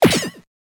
misshit.wav